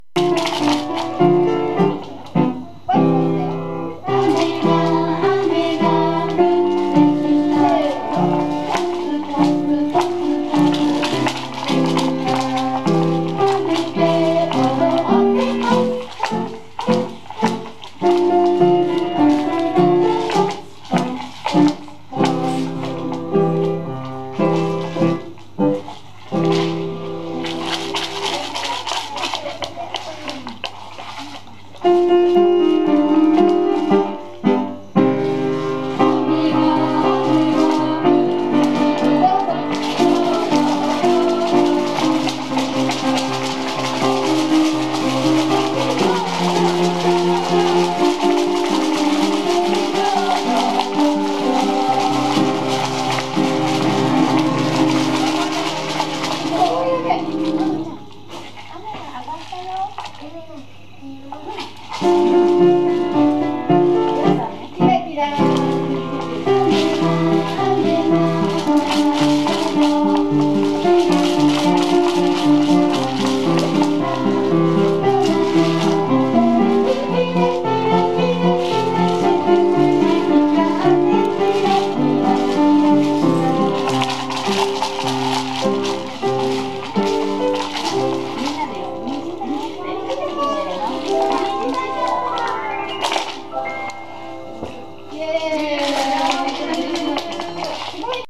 ＜歌入り音源＞